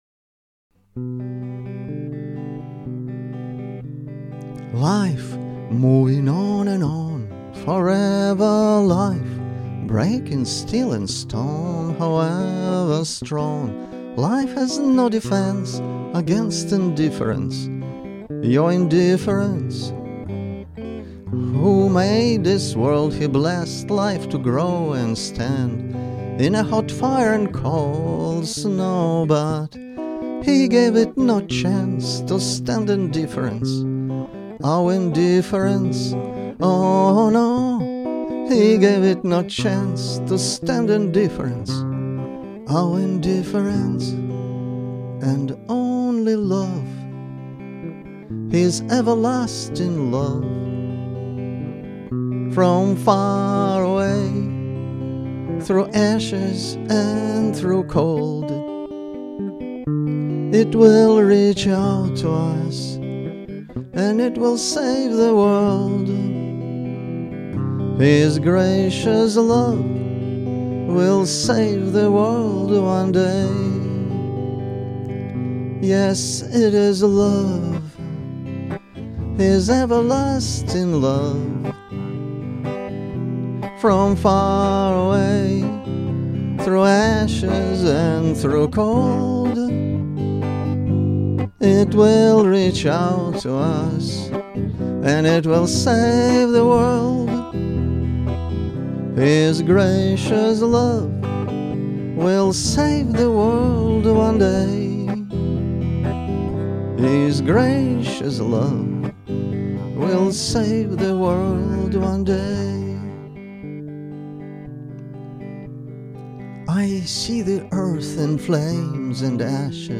(MP3), Guitar Ver 1 (MP3)